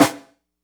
snare.wav